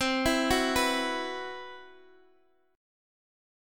Esus2/C Chord